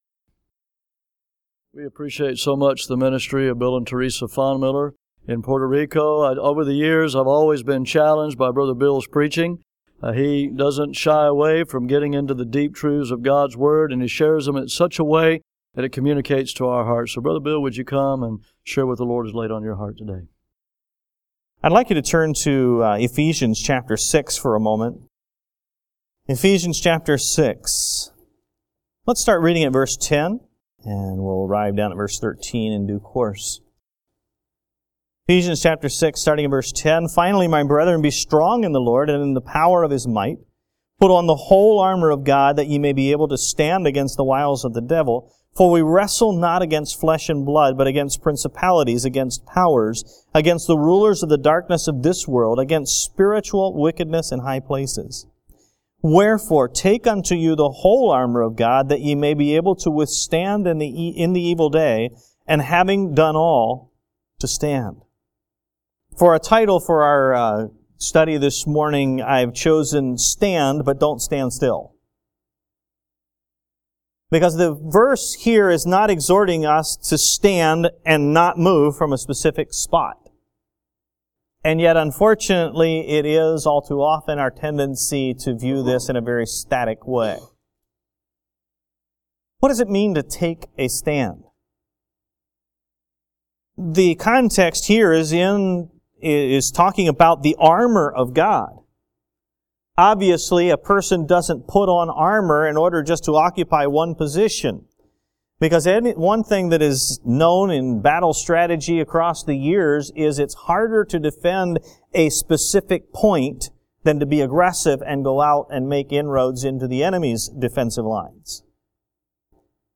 GRACE BIBLE CHURCH Audio Sermons
MORE ADULT SUNDAY SCHOOL CLASS MESSAGES